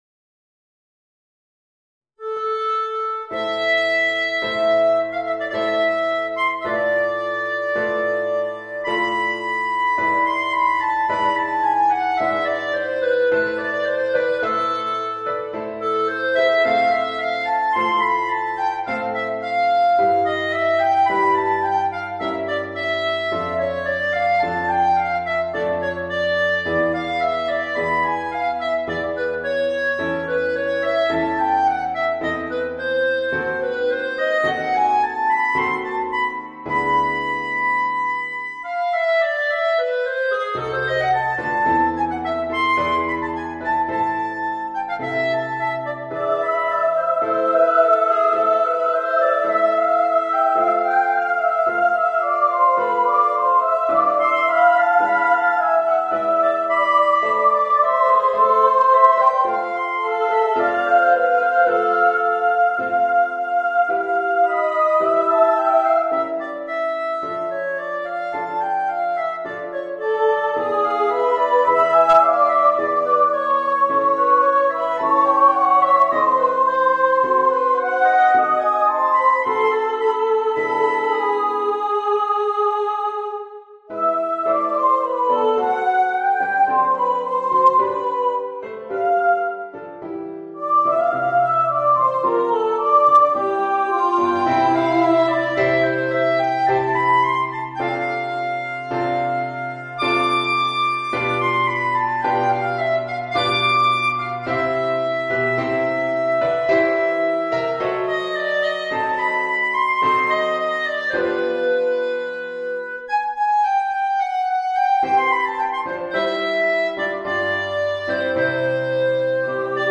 Voicing: Soprano, Clarinet and Piano